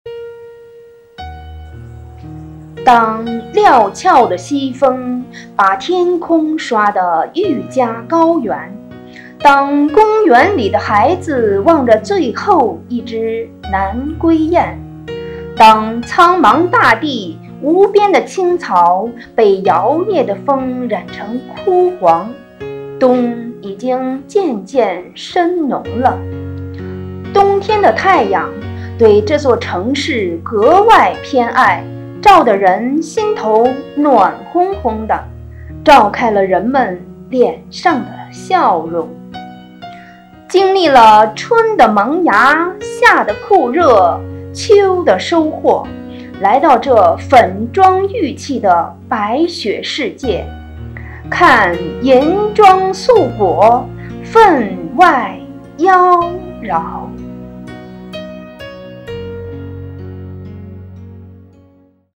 《大好河山·美丽张家口》序言配音